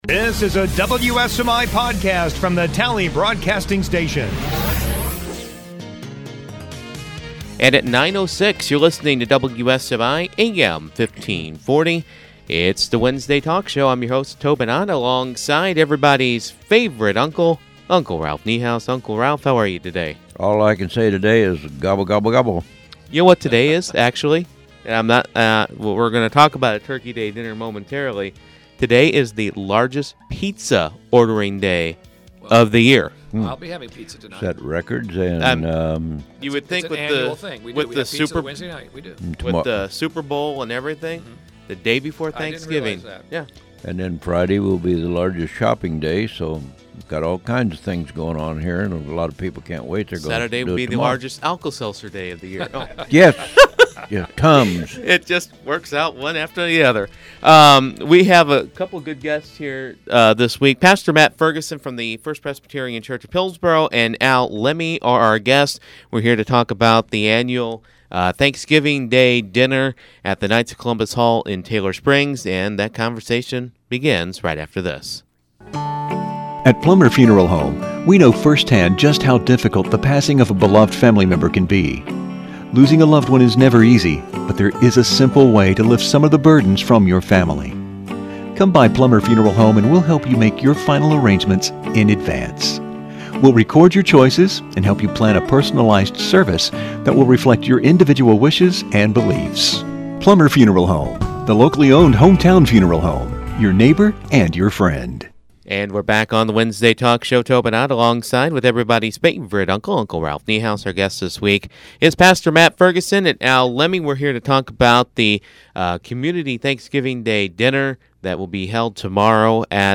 Wednesday Morning Talk Show